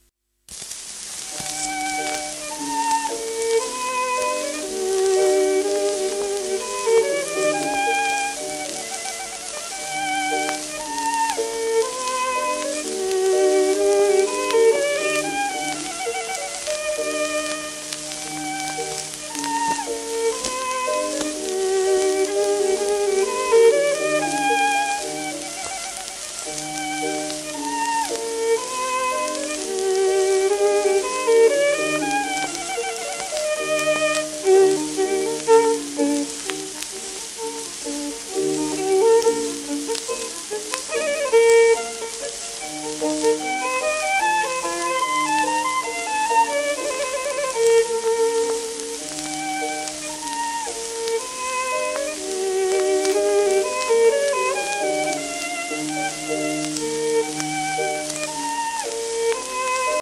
w/ピアノ
盤質B+ *薄い面擦れ、キズ
ドイツ出身。ヨアヒムに学び、ハンス・フォン・ビューローにも認められた腕前を持つ伝説的ヴァイオリニスト。